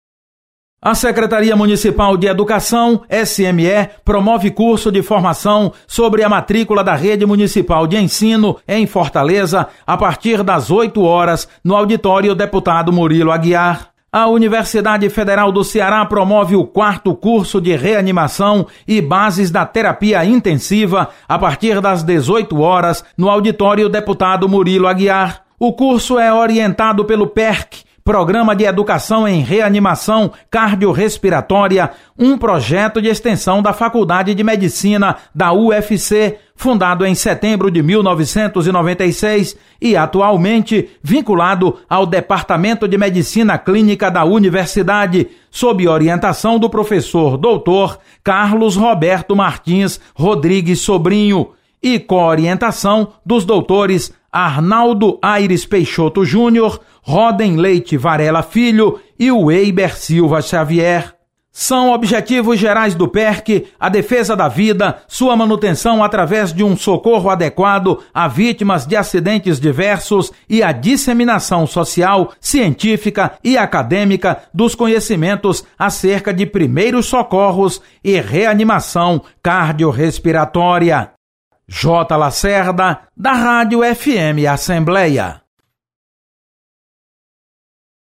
Acompanhe as atividades da Assembleia Legislativa nesta segunda-feira (22/05). Repórter